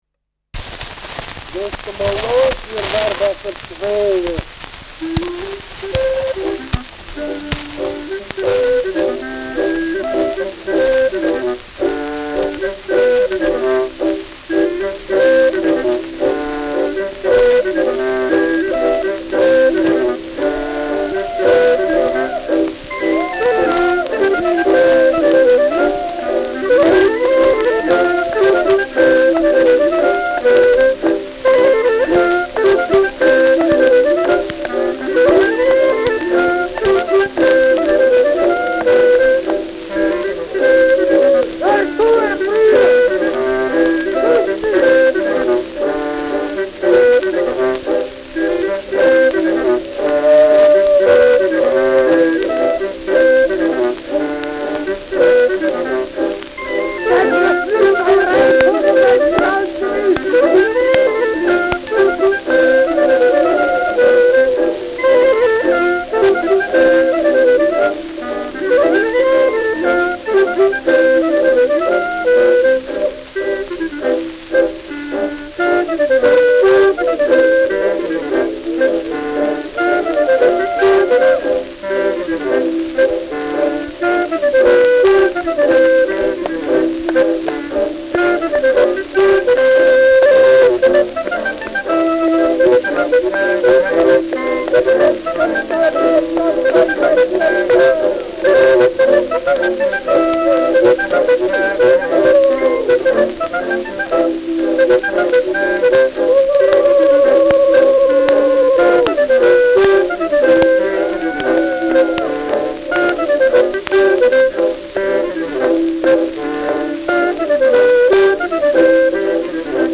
Schellackplatte
häufiges Knacken : präsentes Rauschen : abgespielt : leiert
Bácskaer Streich-Kapelle (Interpretation)